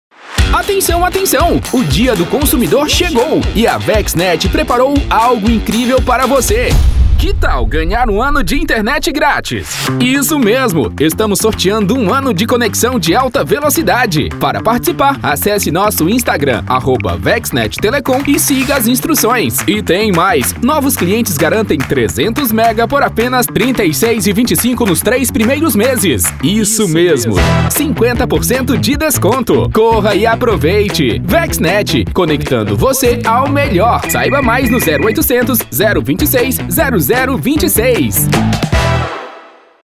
Ceará
Demo VexNet Jovem: